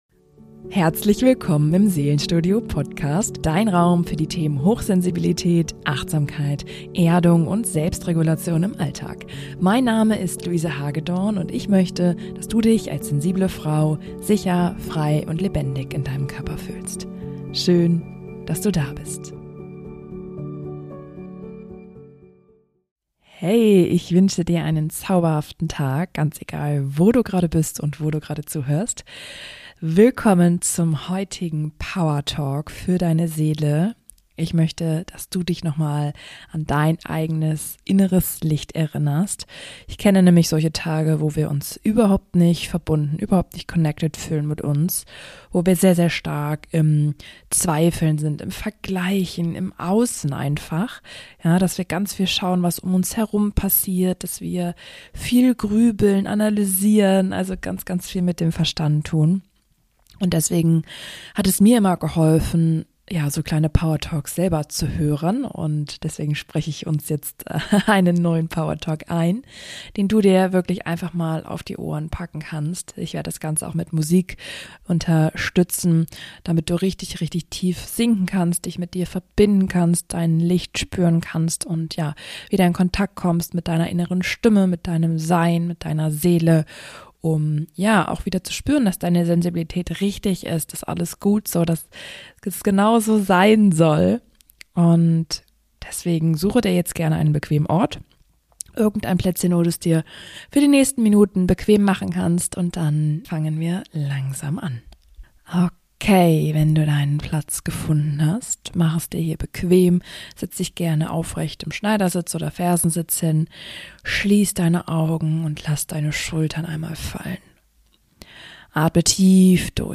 Mit sanfter Musik, bewusster Atmung und Worten, die dich daran erinnern: Du bist genau richtig.